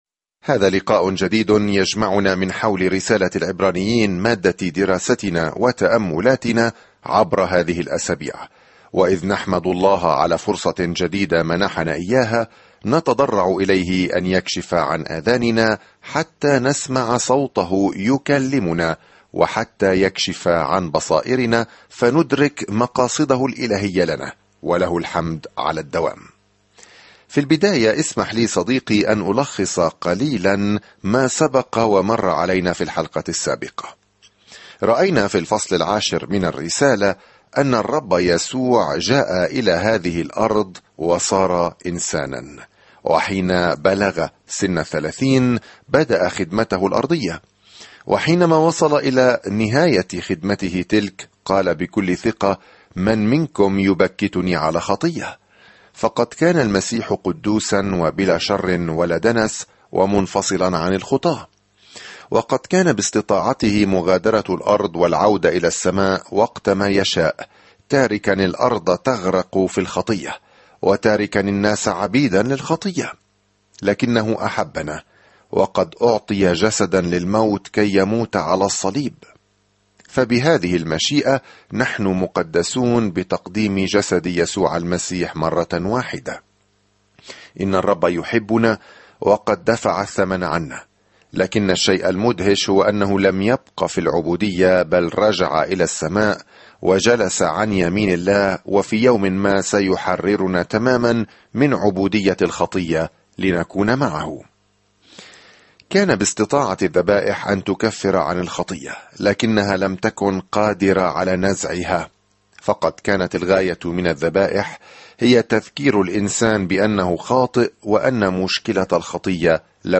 سافر يوميًا عبر العبرانيين وأنت تستمع إلى الدراسة الصوتية وتقرأ آيات مختارة من كلمة الله.